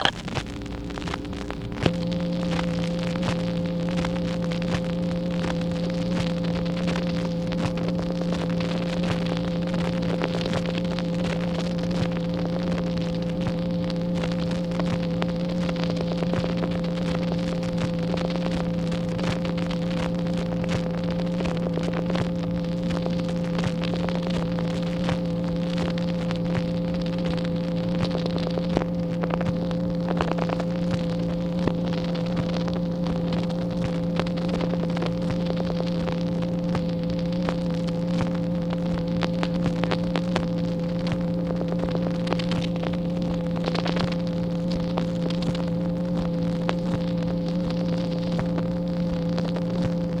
MACHINE NOISE, September 8, 1964
Secret White House Tapes | Lyndon B. Johnson Presidency